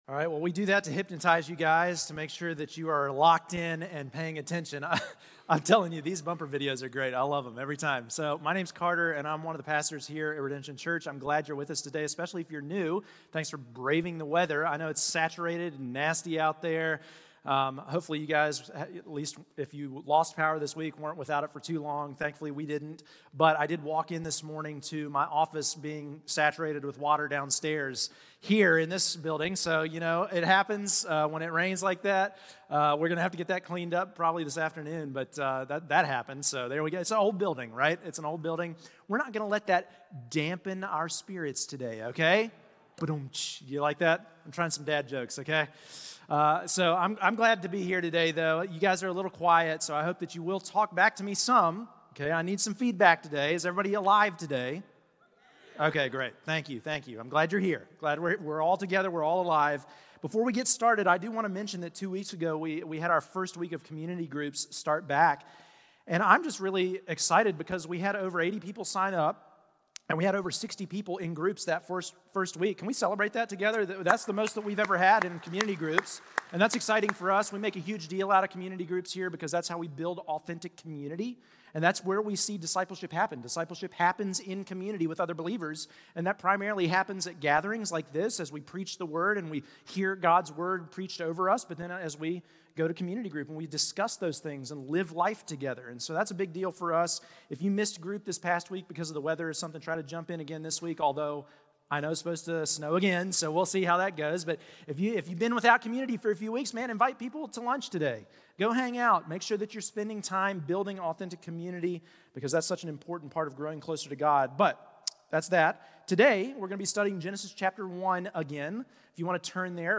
Redemption Church Sermons You & Yourself Feb 16 2025 | 00:45:15 1x 00:00 / 00:45:15 Subscribe Share Apple Podcasts Spotify Overcast RSS Feed Share Link Embed